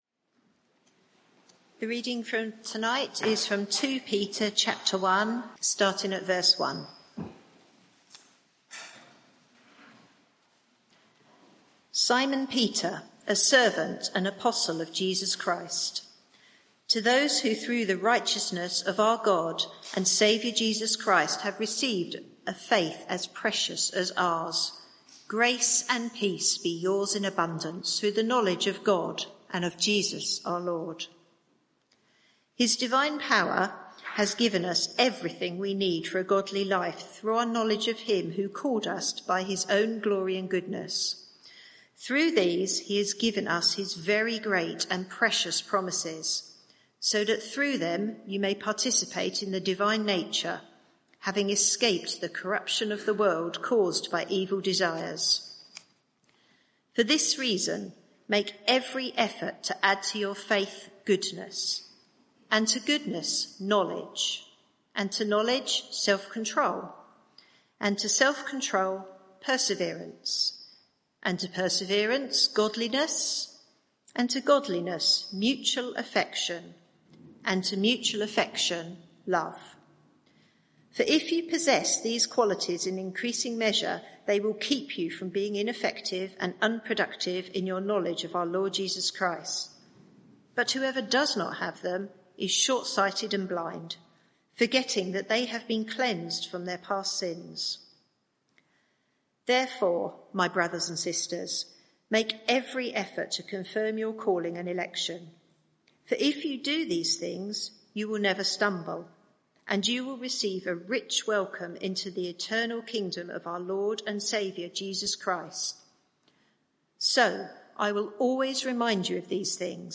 Media for 6:30pm Service on Sun 01st Sep 2024 18:30 Speaker
Passage: 2 Peter 1:1-12 Series: Stable and growing Theme: Sermon (audio)